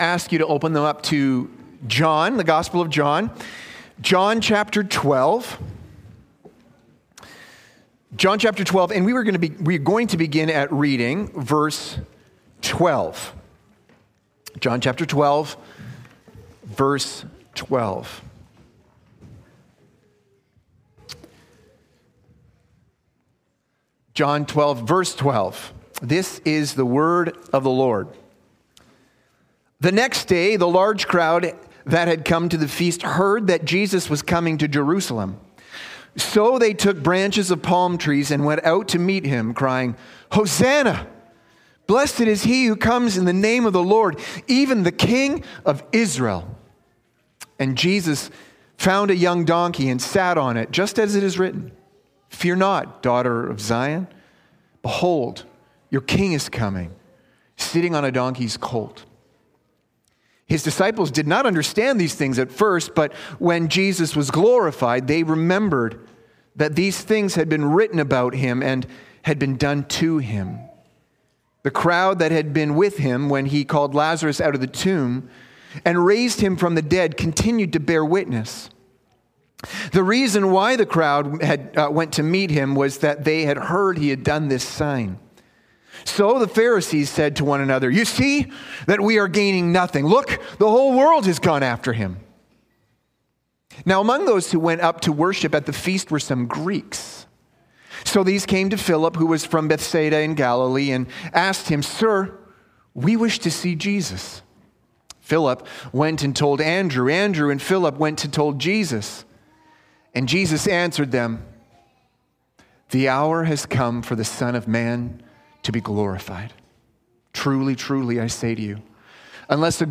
Sermons | Park City Gospel Church